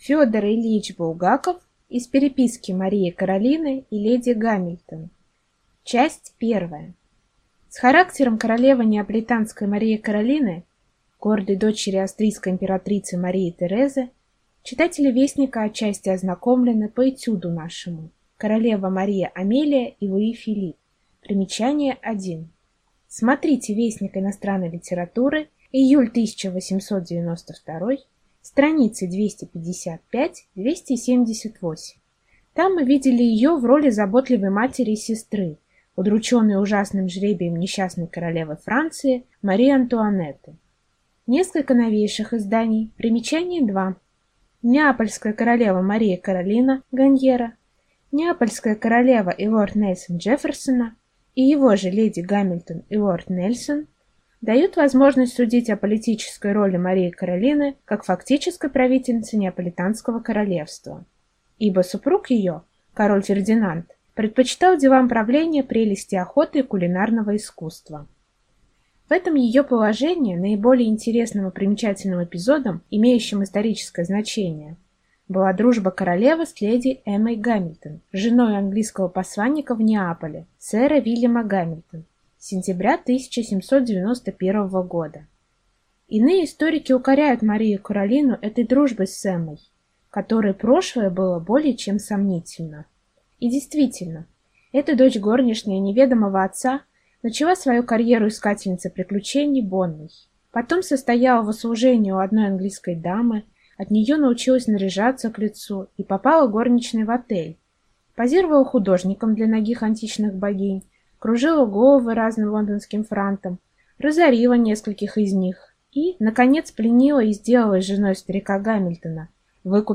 Аудиокнига Из переписки Марии-Каролины и леди Гамильтон | Библиотека аудиокниг